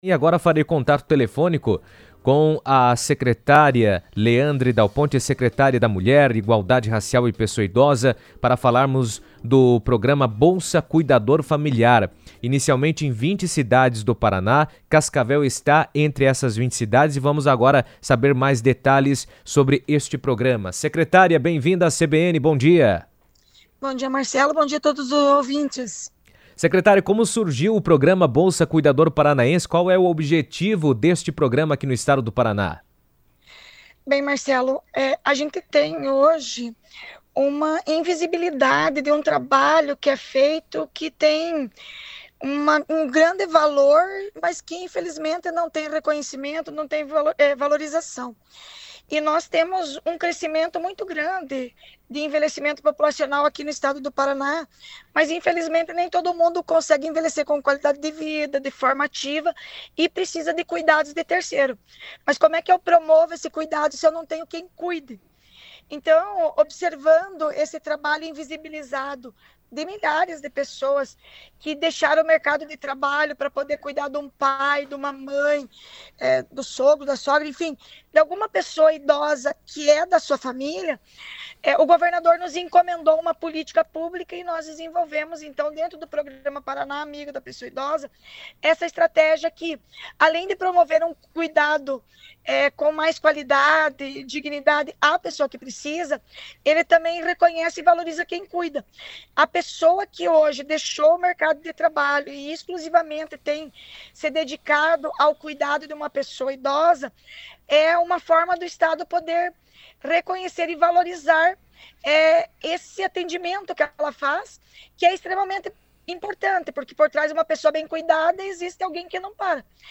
Cascavel está entre as 20 cidades do Paraná selecionadas para participar do programa Bolsa Cuidador Familiar, que oferece auxílio financeiro a familiares que cuidam de pessoas idosas. Em entrevista por telefone à CBN, Leandre Dal Ponte, da Secretaria da Mulher, Igualdade Racial e Pessoa Idosa, destacou a importância da iniciativa para apoiar os cuidadores e garantir mais qualidade de vida às famílias beneficiadas.